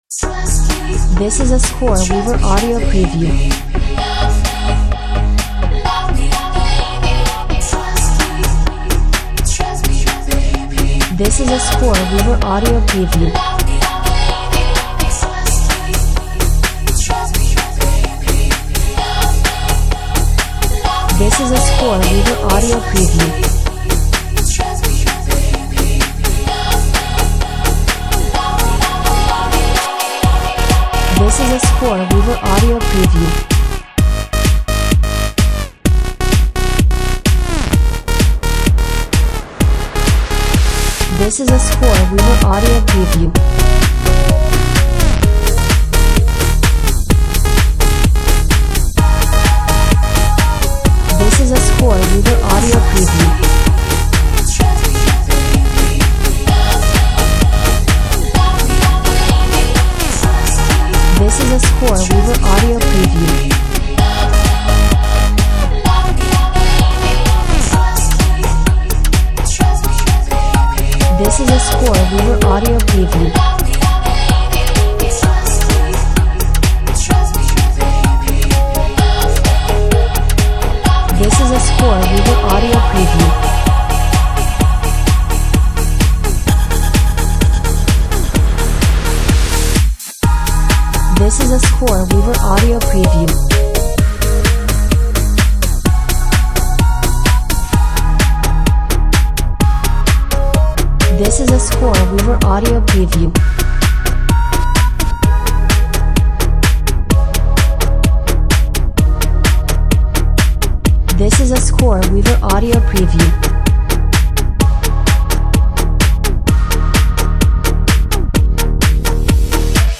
Vocal Techno House at it’s finest- full of positive energy!